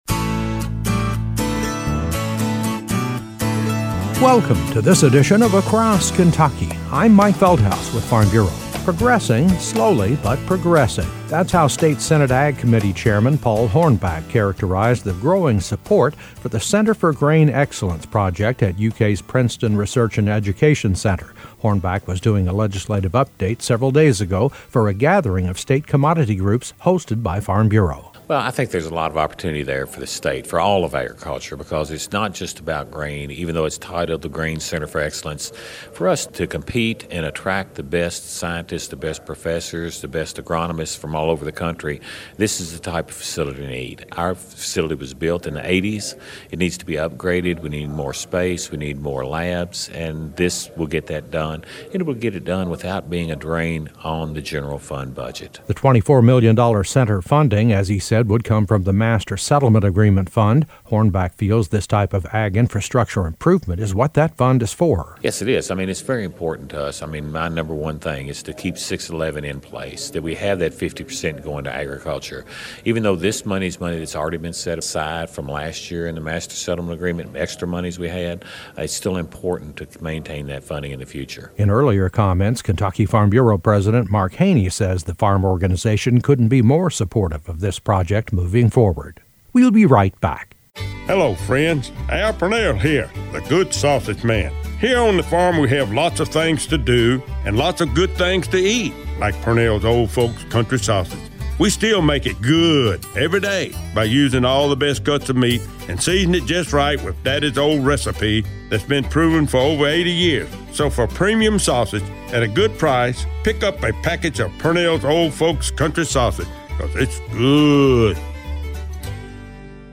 Across KentuckyA feature report with Kentucky Senate Ag Committee Chairman, Paul Hornback, on a few observations as we head into the 2016 session of the general assembly. He discusses the proposed Center for Grain Excellence and the ongoing challenge to protect Tobacco Settlement funding dedicated to rebuilding ag infrastructure to boost net farm income as tobacco income declines.